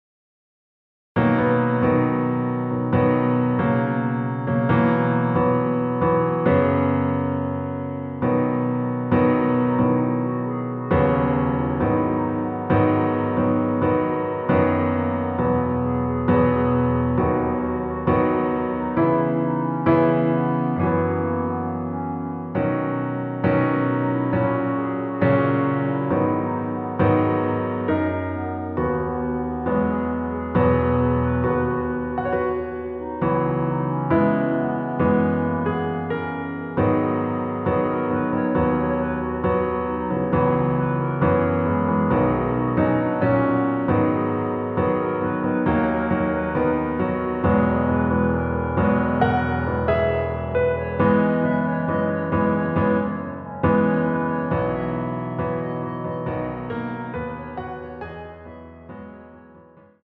반주를 피아노 하나로 편곡하여 제작하였습니다.
원키에서(-2)내린 (Piano Ver.)멜로디 MR입니다.